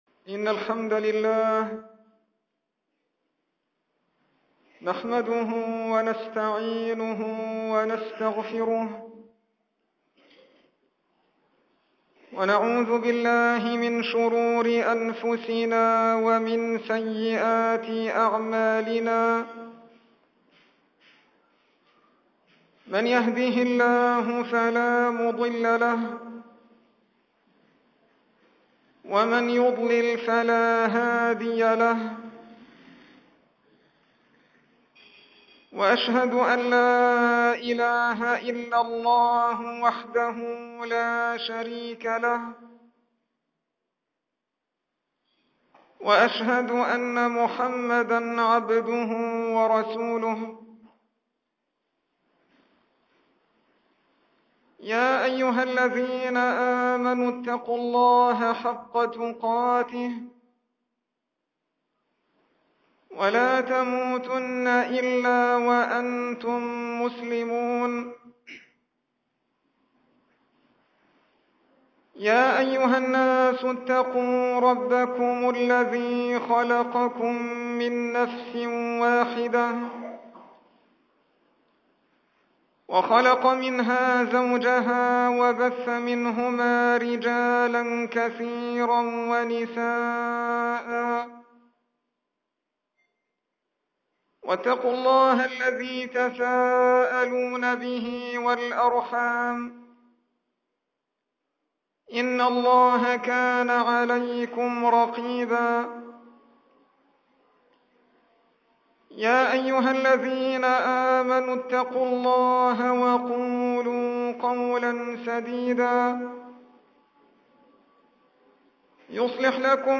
الصفحة الرئيسية » الخـطب » خطب عامة Share | هذا بيان للناس (تعليقا على الأحداث وفتنة الدستور) 5176 زائر 07/12/2012 admin الجمعة 23 محرم 1434 هـ الموافق 7 ديسمبر 2012 م | حفظ | ( ) | حفظ , | ( ) 0 صوت